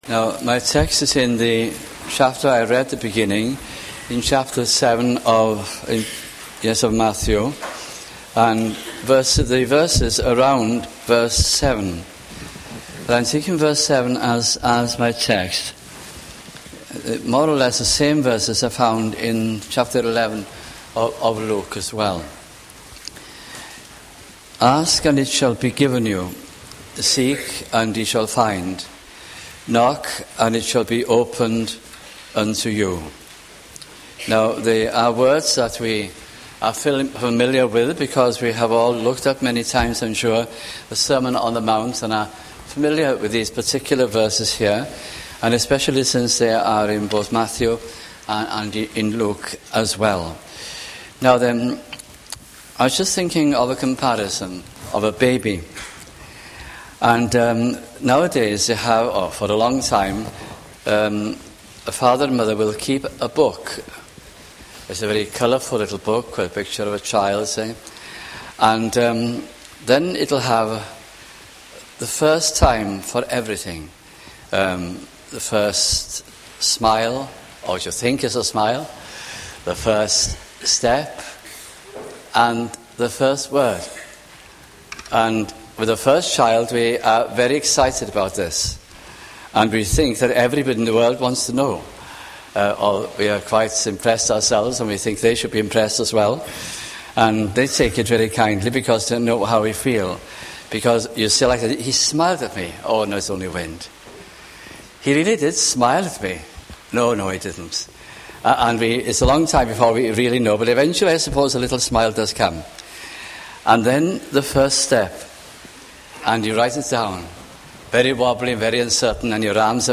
» Prayer 1999 » sunday morning messages